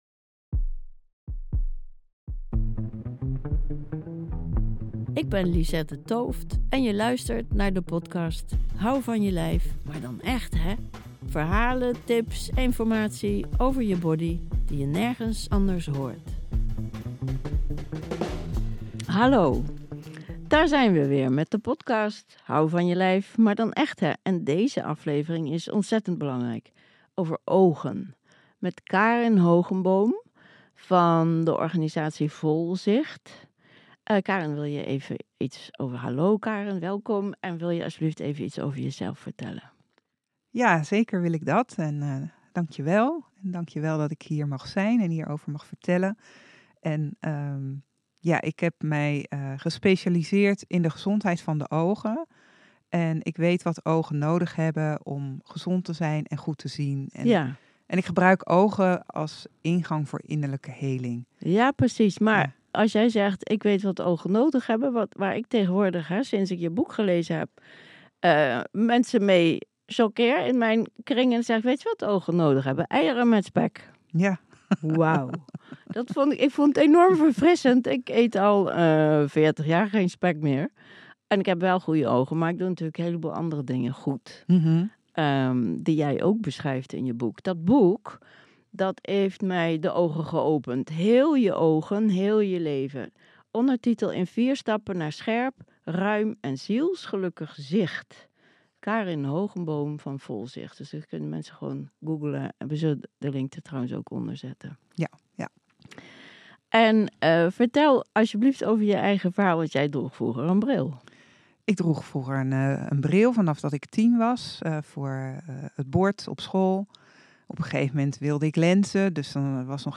Een fascinerend gesprek over de weg naar ruimer zicht, en hoe je ziel gelukkig wordt van breder kijken!
Deze aflevering is wederom opgenomen in de podcast studio van Sound Art Studio's